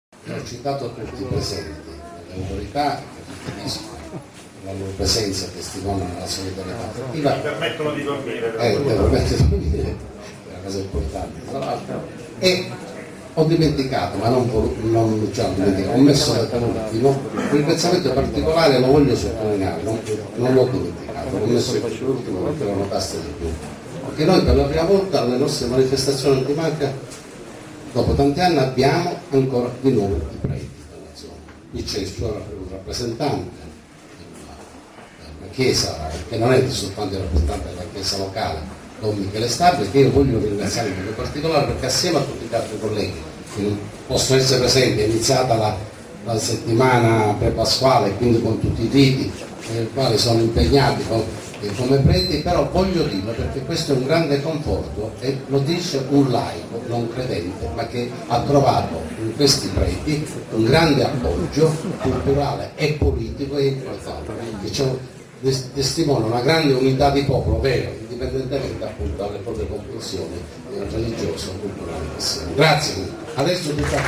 ore 17 presso il Centro Esposizione Calicar (via Gelsi Neri, svincolo autostradale di Altavilla Milicia - Pa)